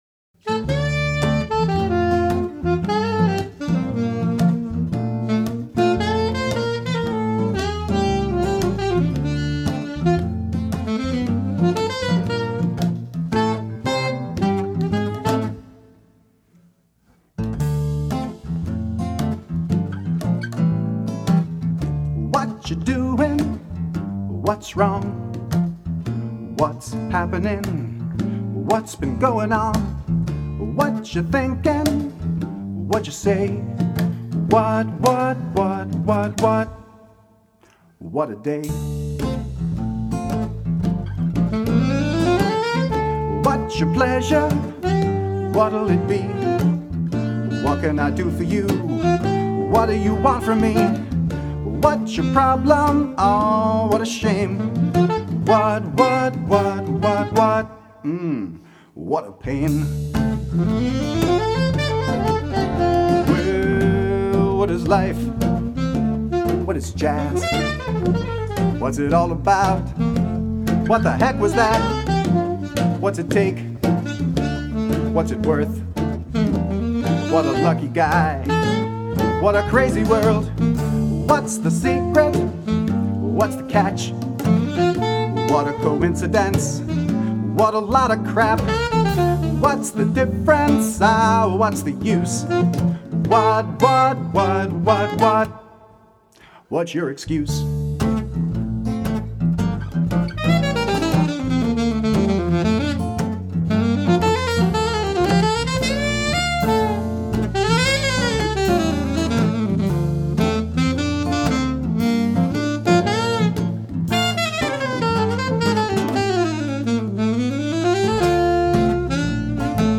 Guitar & Vocals
bass guitar
alto saxophone
percussion.